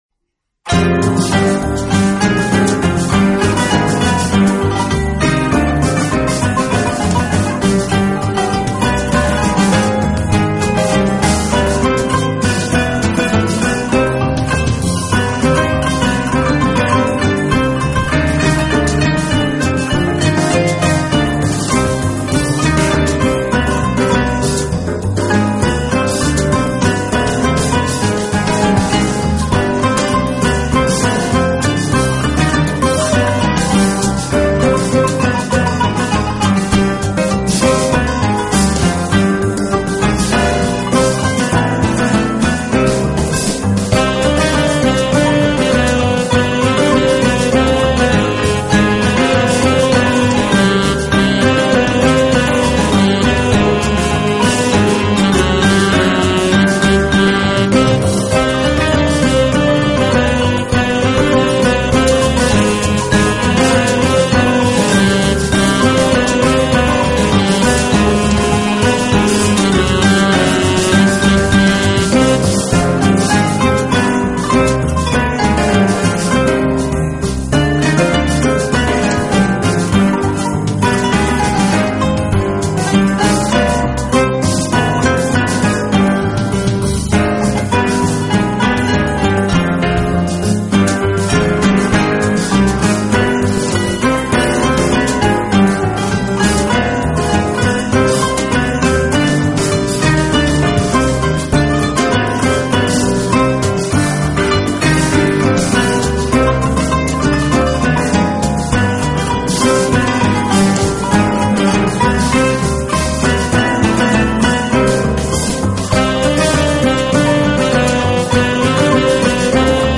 【浪漫钢琴】